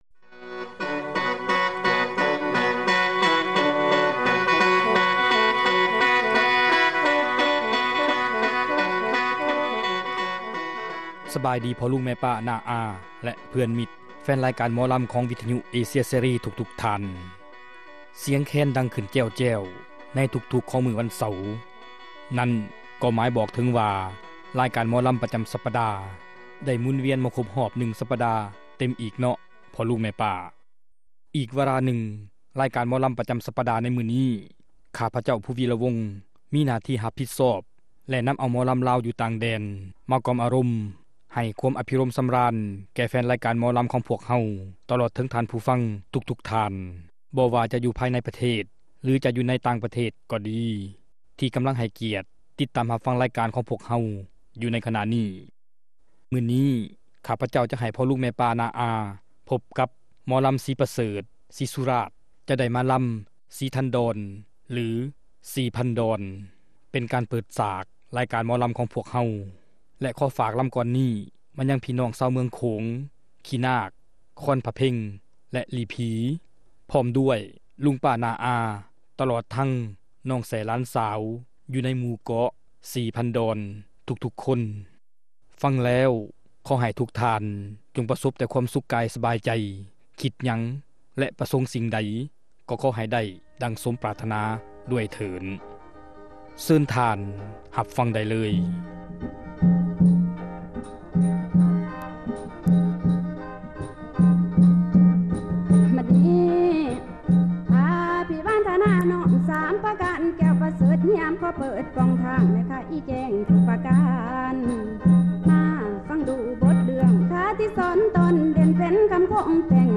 ຣາຍການໜໍລຳ ປະຈຳສັປະດາ ວັນທີ 29 ເດືອນ ກໍຣະກະດາ ປີ 2005